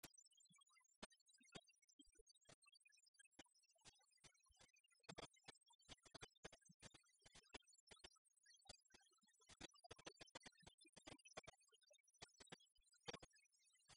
Note polka Localisation Coëx
Thème : 1074 - Chants brefs - A danser
Résumé Garçon qui a fait son parapluie Fonction d'après l'analyste danse : polka